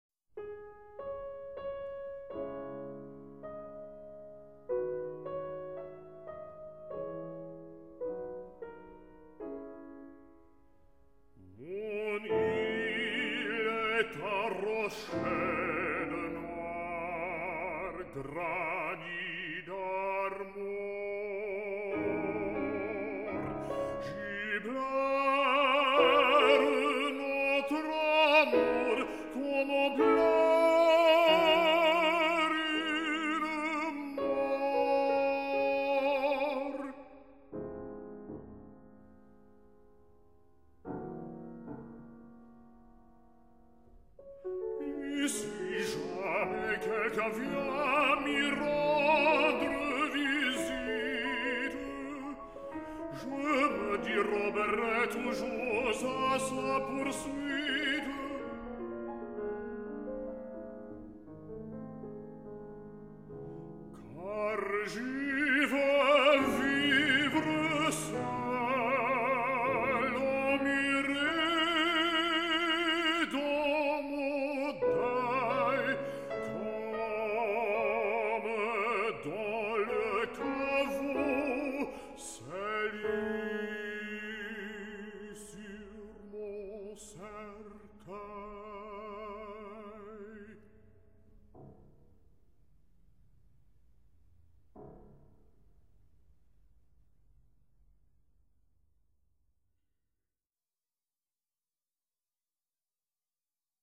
Baritone
Piano